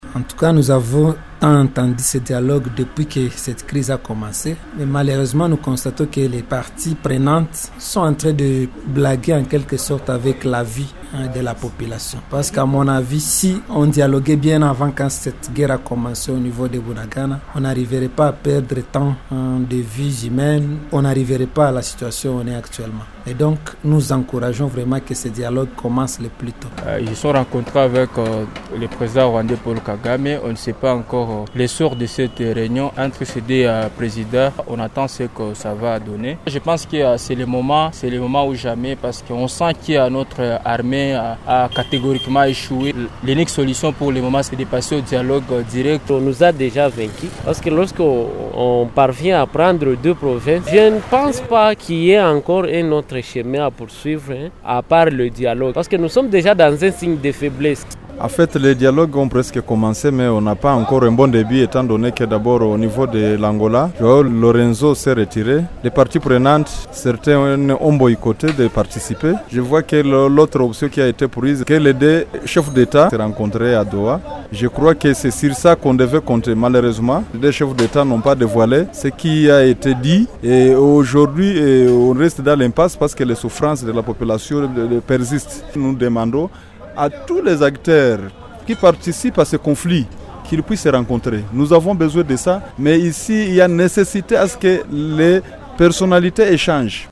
Certains d’entre eux se sont exprimés au micro de Radio Maendeleo